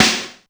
• Acoustic Snare Sound A Key 353.wav
Royality free snare one shot tuned to the A note. Loudest frequency: 3183Hz
acoustic-snare-sound-a-key-353-AHW.wav